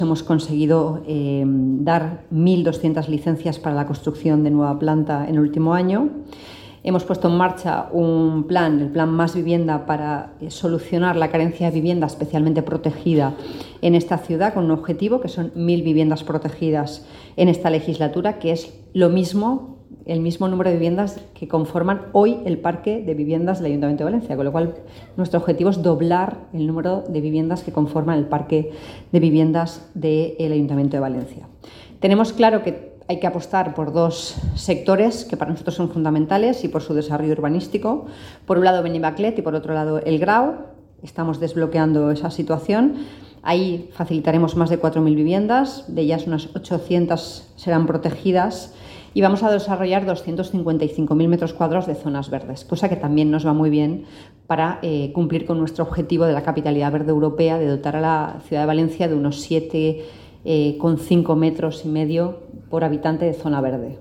Clausura Encuentro inmobiliario - València